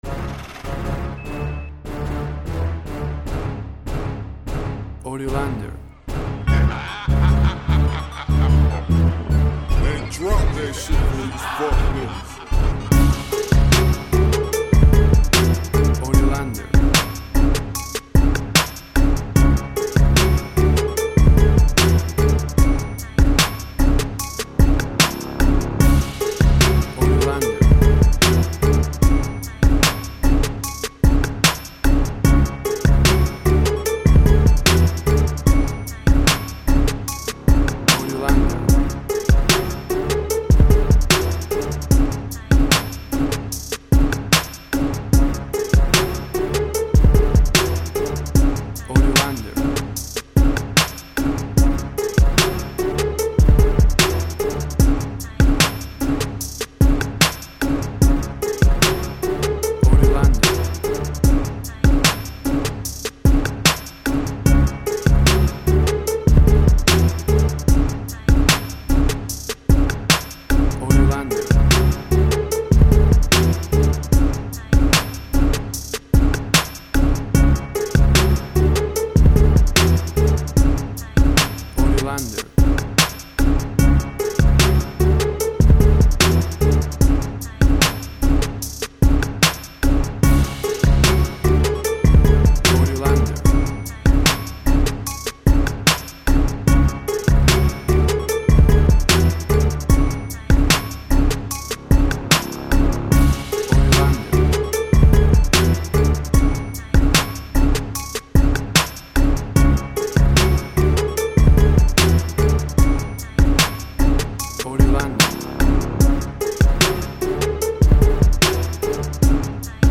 Tempo (BPM): 75